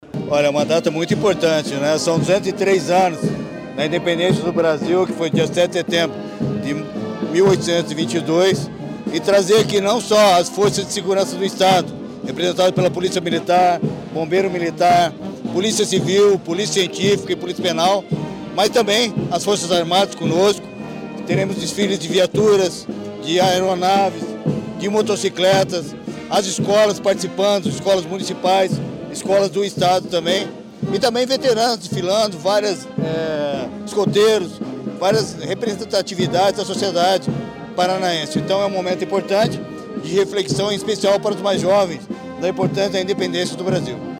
Sonora do secretário Estadual da Segurança Pública, Hudson Teixeira, sobre o desfile de 7 de setembro